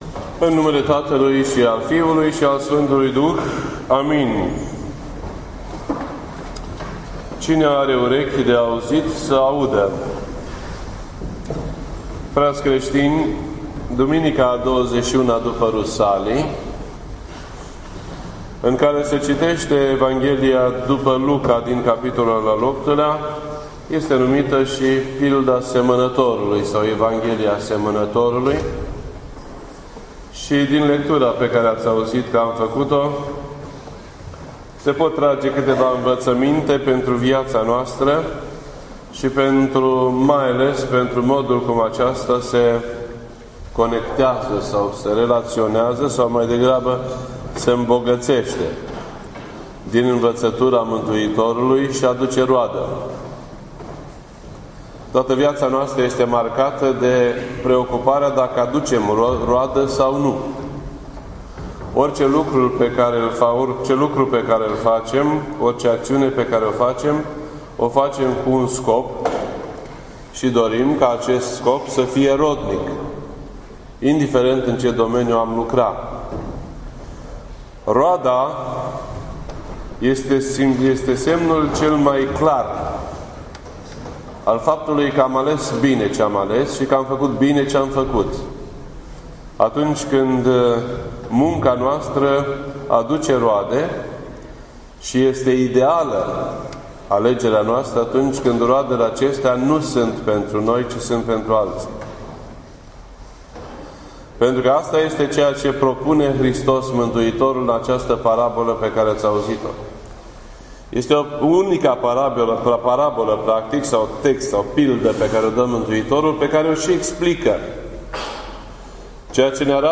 This entry was posted on Sunday, October 11th, 2015 at 11:41 AM and is filed under Predici ortodoxe in format audio.